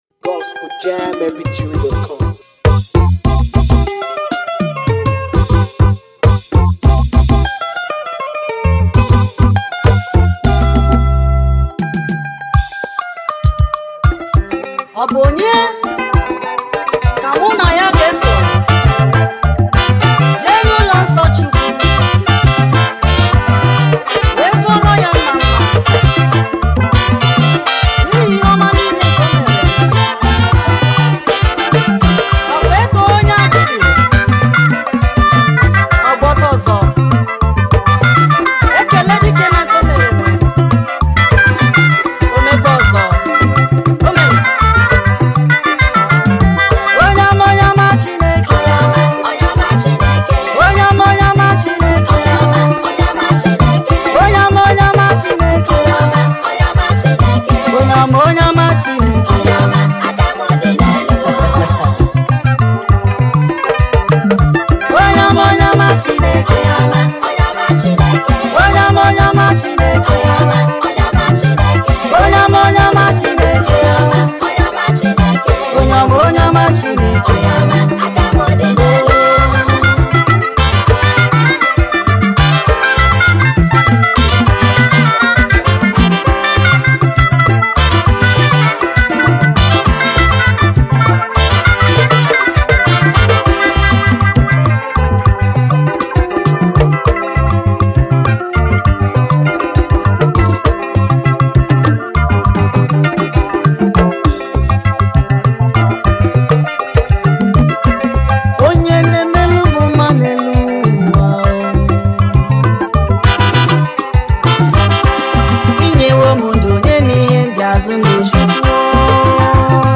Afro beatmusic
Through his unique medley style